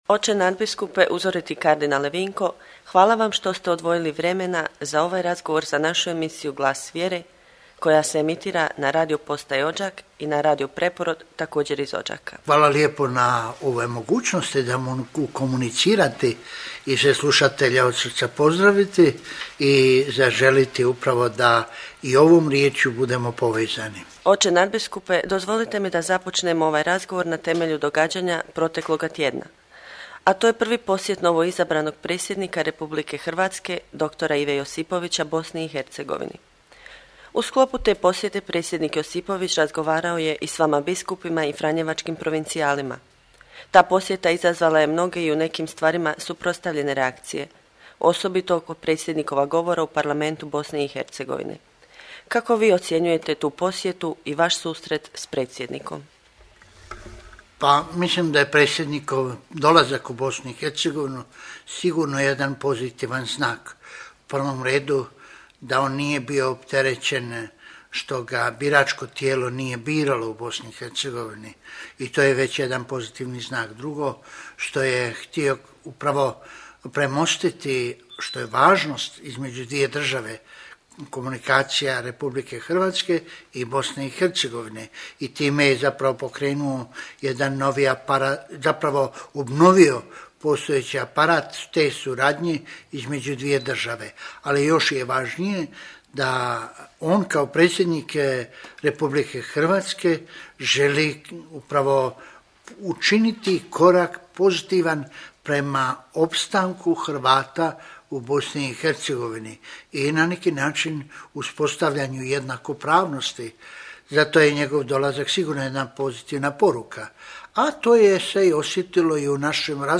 Audio: Razgovor s kardinalom Vinkom Puljićem
Kardinal Vinko Puljić, nadbiskup metropolit vrhbosanski govori za emisiju "Glas vjere" Radio postaje Odžak prigodom Sv. Krizme u župi Glavosjeka sv. Ivana Krstitelja u Odžaku.